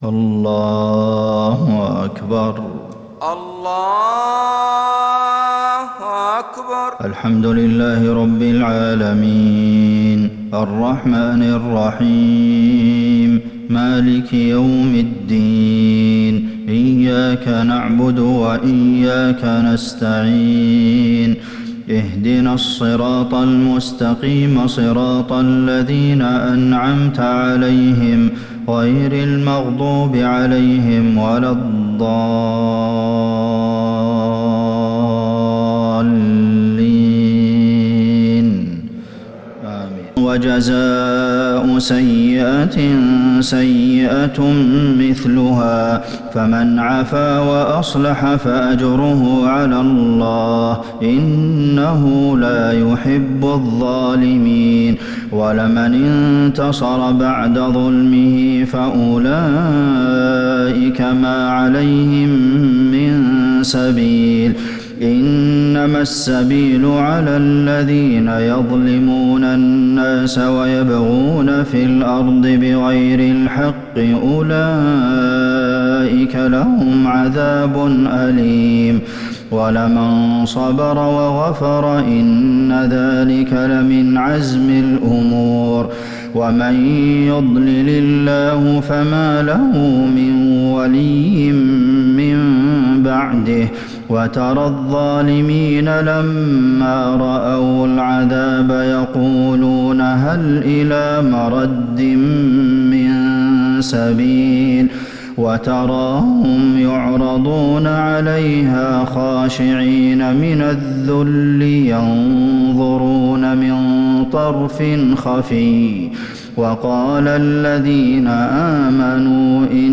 صلاة الفجر للشيخ عبدالمحسن القاسم 17 شعبان 1441 هـ
تِلَاوَات الْحَرَمَيْن .